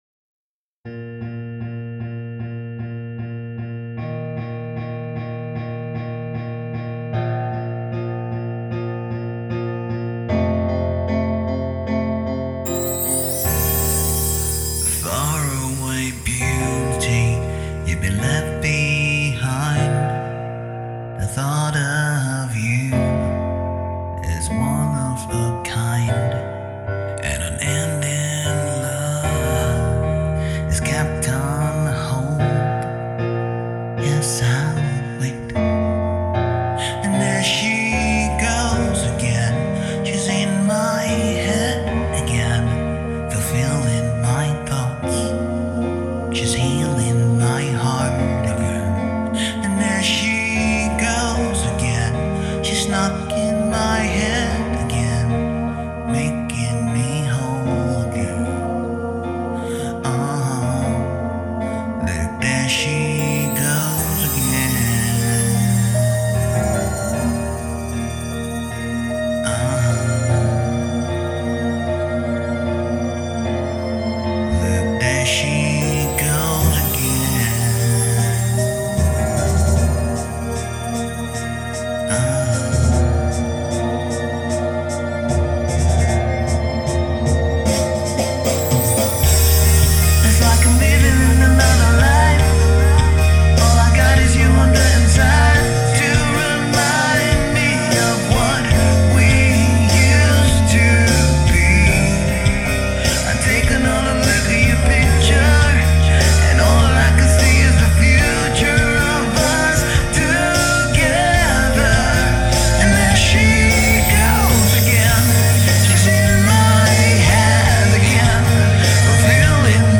Ballad, Blues